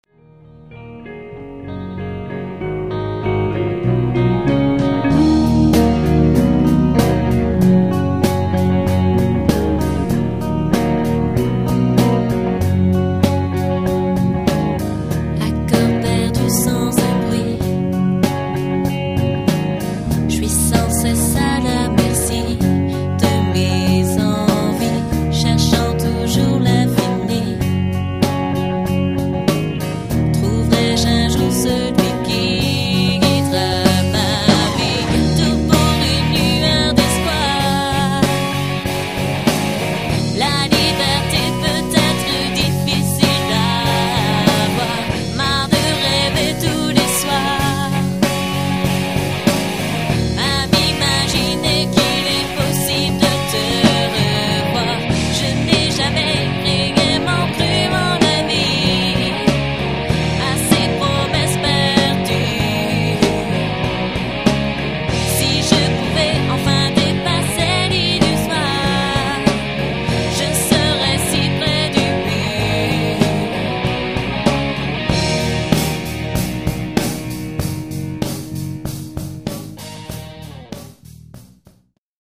Album autoproduit enregistré maison...